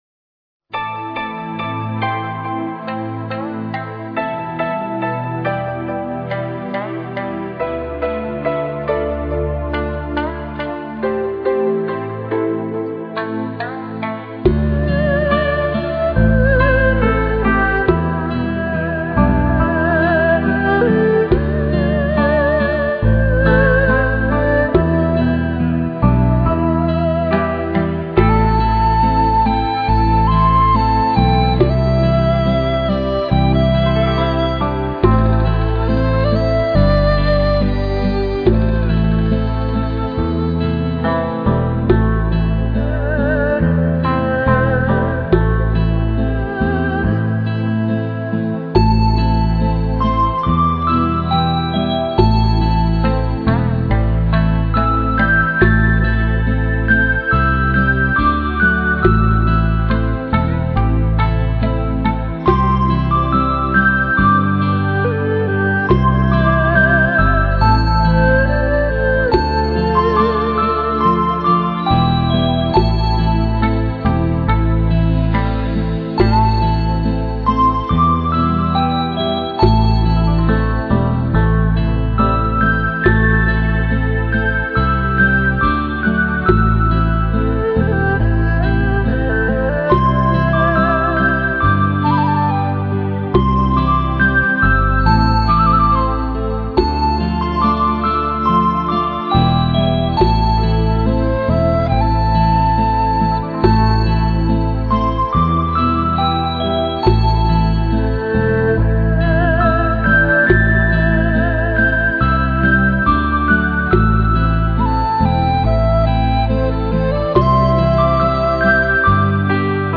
佛音 冥想 佛教音乐 返回列表 上一篇： 纯音乐-南无观世音菩萨--水晶佛乐 下一篇： 峨眉金顶(纯音乐